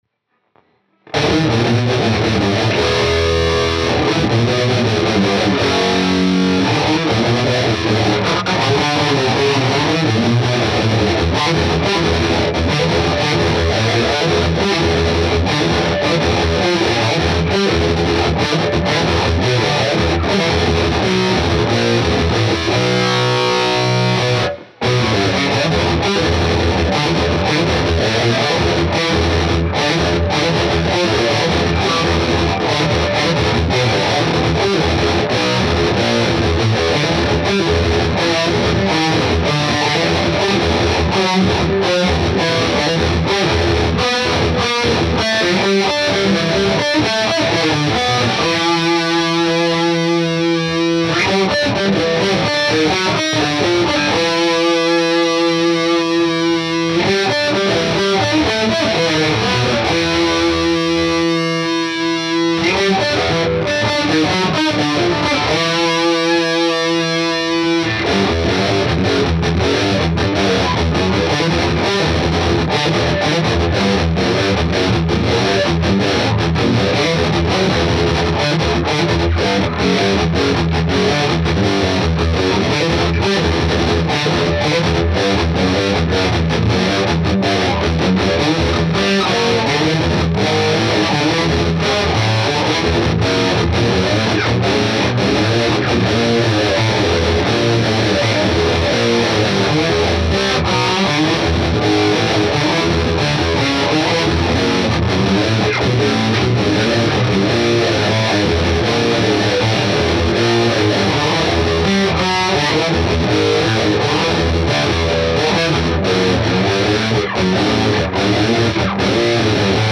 Yaklaşık 4.5 aydır gitar çalıyorum.